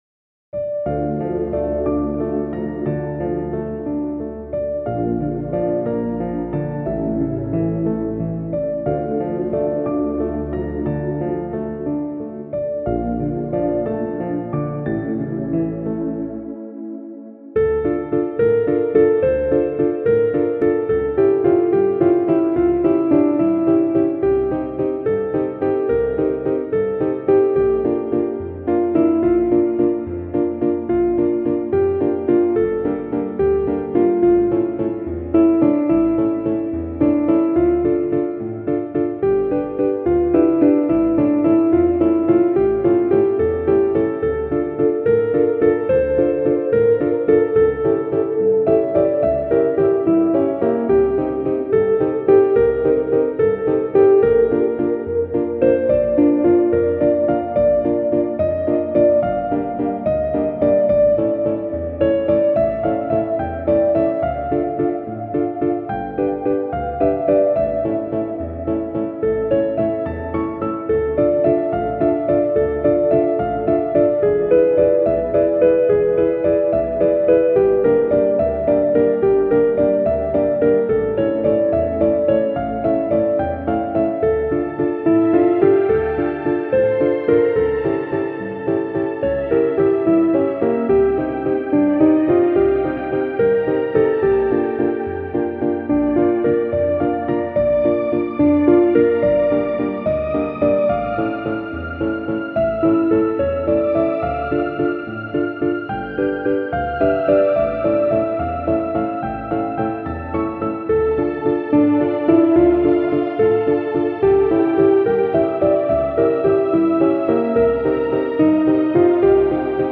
Spacesynth Trance Techno Relax
Meditative Newage Space